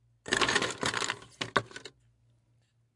描述：从装满冰块的容器中舀出冰块，冰块打在舀子上，冰块打在冰上
Tag: 刮取 容器